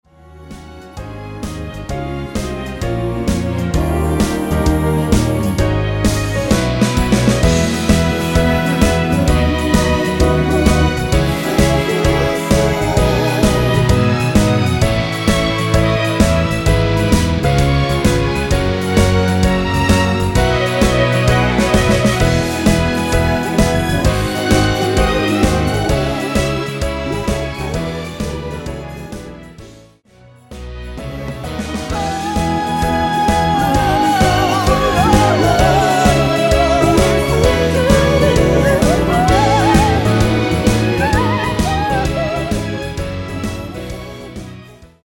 원키 코러스 포함된 MR이며 원키(멜로디,코러스 MR)애서 전체 미리듣기 가능하니 확인후 이용하세요.
전주 없는 곡이라 전주 2마디 만들어 놓았습니다.
엔딩이 페이드 아웃이라 라이브 하시기 편하게 엔딩을 만들어 놓았습니다.
Db